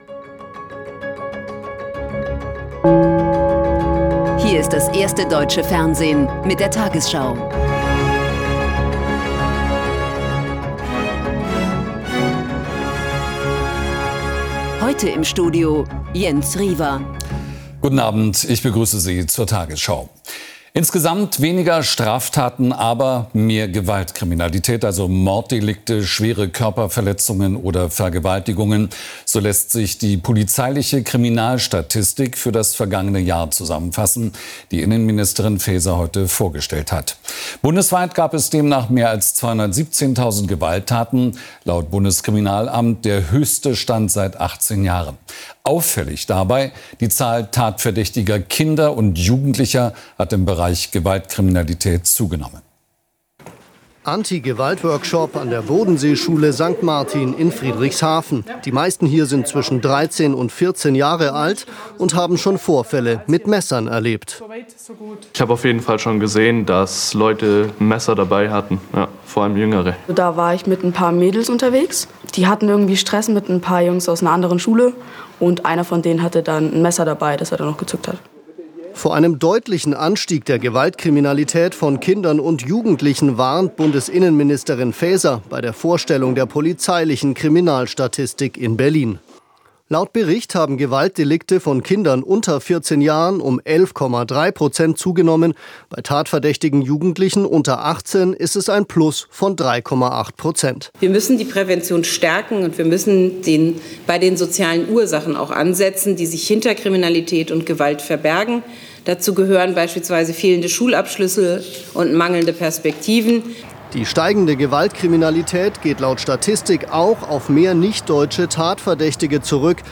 Deutschlands erfolgreichste Nachrichtensendung als Audio-Podcast.